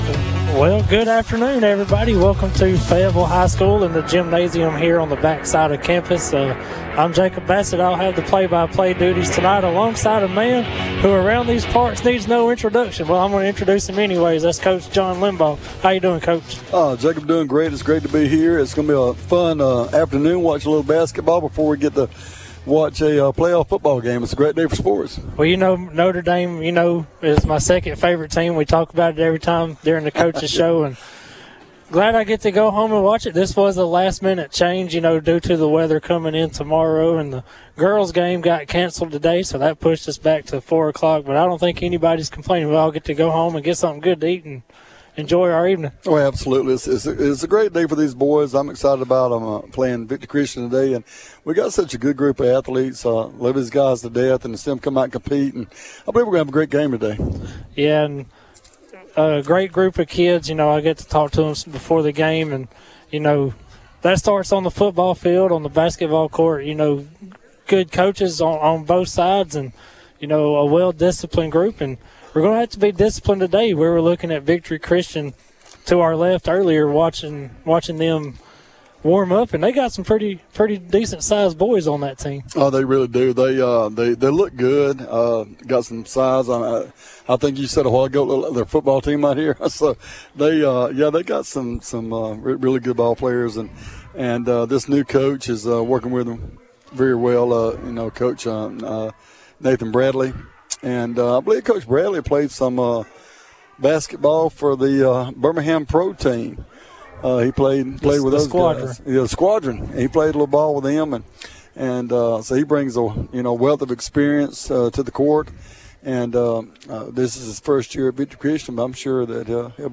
(Boys Basketball) Fayetteville vs. Victory Christian